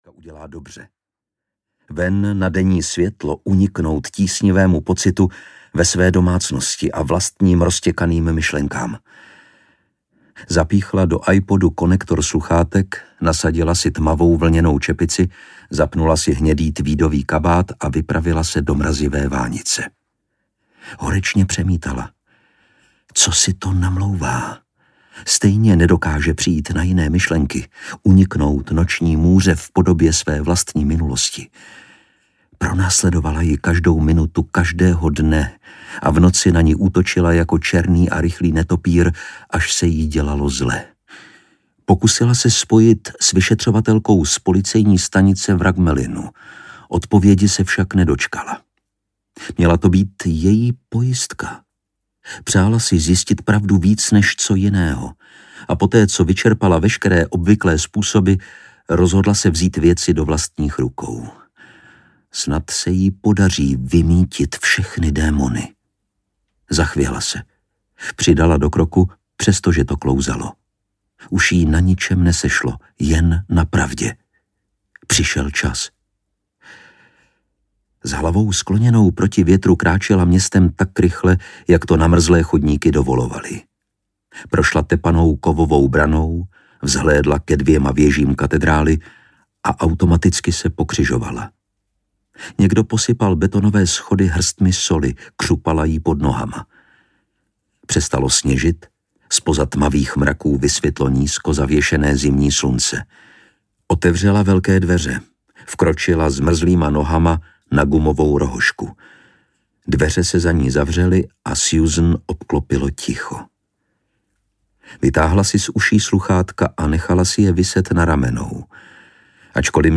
Andělé smrti audiokniha
Ukázka z knihy
• InterpretLukáš Hlavica